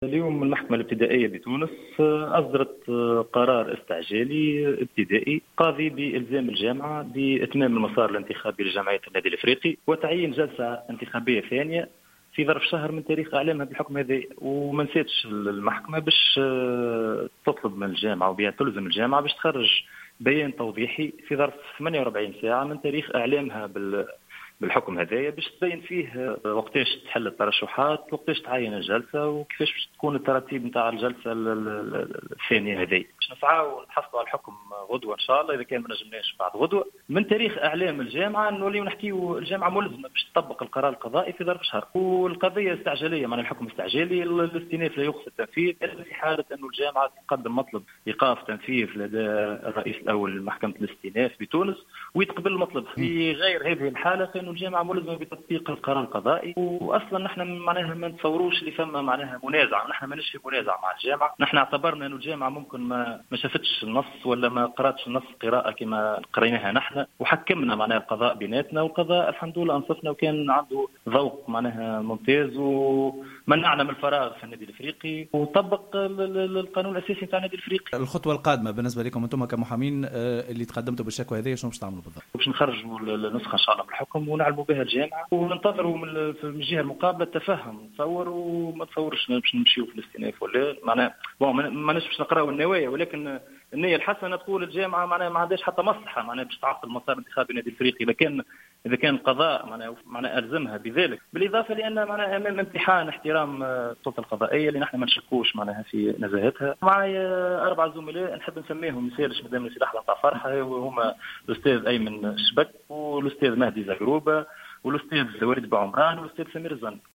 تصريح خاص للجوهرة اف ام